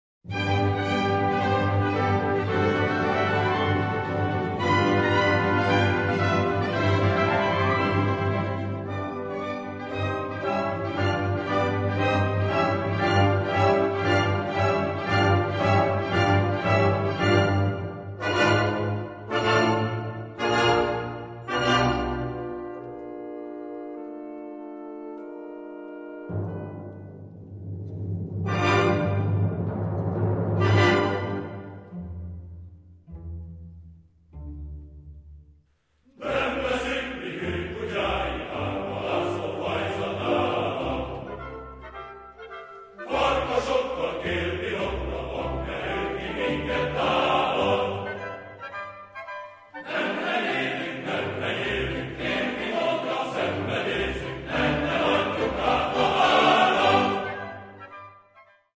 The recording was made in Budapest, in 1984..
Chorus